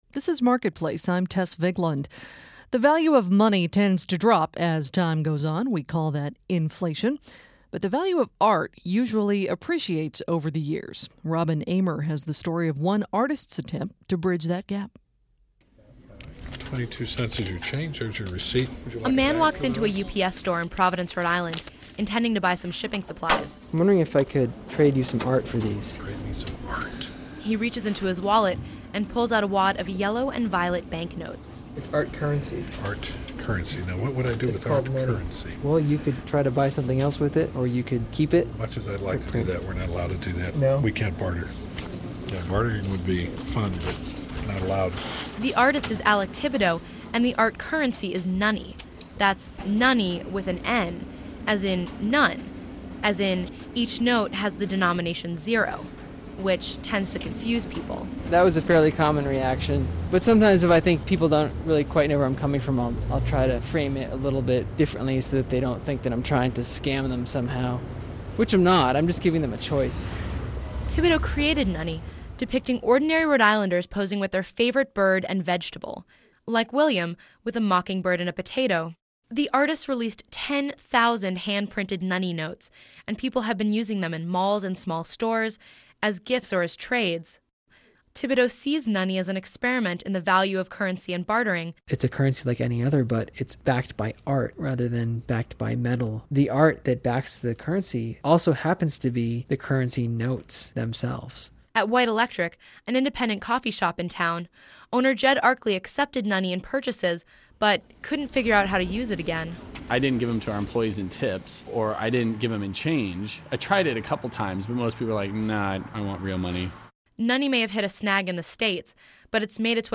This piece originally aired on the public radio program Marketplace on April 6, 2005.